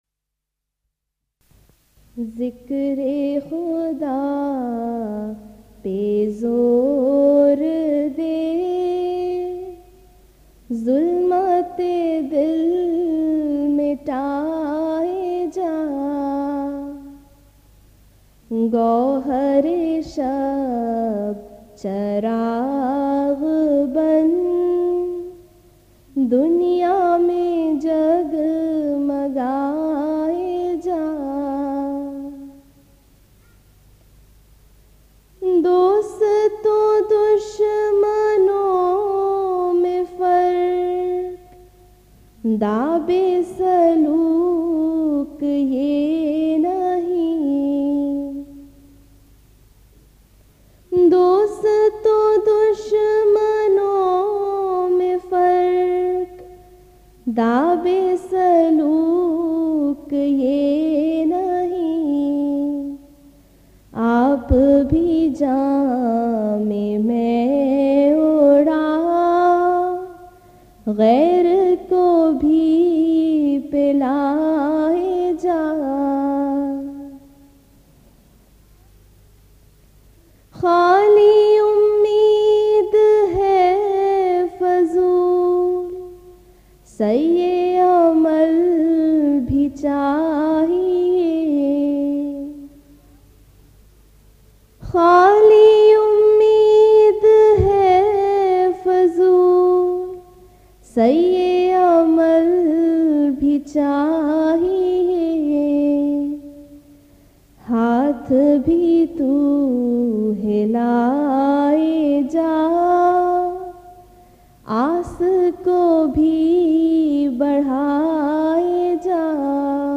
Voice: Member Lajna Ima`illah